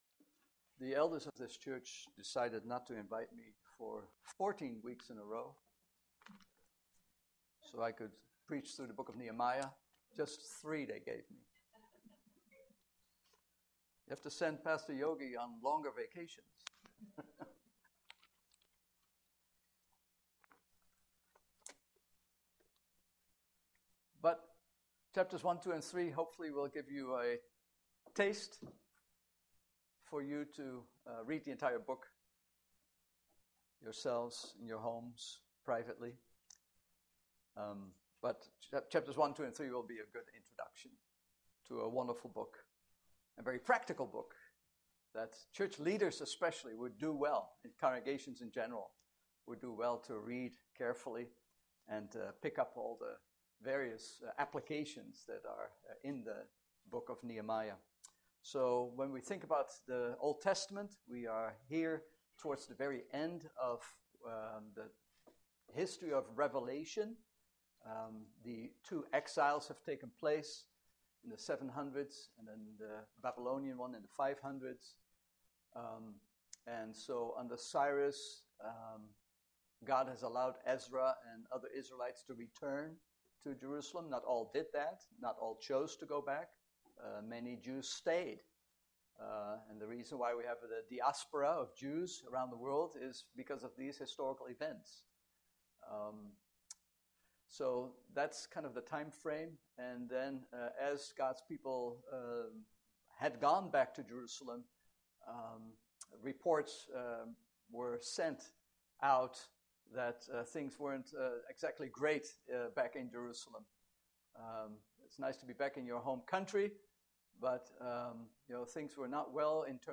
Nehemiah 1:1-11 Service Type: Morning Service « Believer’s Resurrection Do You Have Keys to the Church?